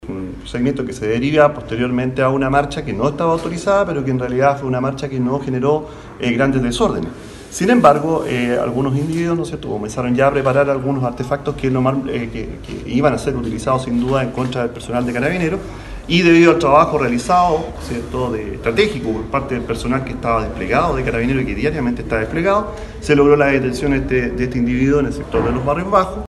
Así lo explicó el Jefe de Zona de Carabineros de Los Ríos, General Iván Keterer, quien aseveró que tras el trabajo realizado por personal policial se detuvo a un hombre que portaba los elementos incendiarios.